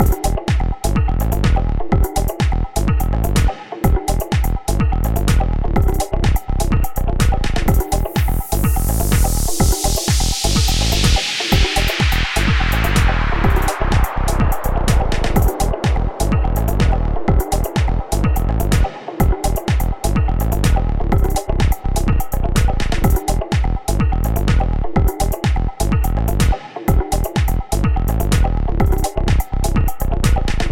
I’ve prepared an electro style loop using a Drum Rack for drums, a percussion loop that resides in another audio channel, a bounced audio synth base loop, and a white noise effect.
Listen to the loop:
electro-loop.mp3